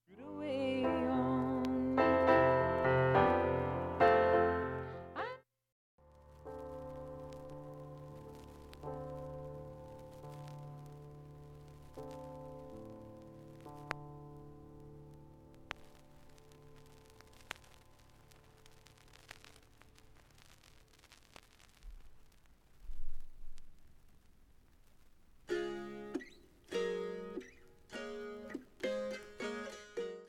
音質良好全曲試聴済み。 瑕疵部分 B-3後半にかすかなプツが１回出ます。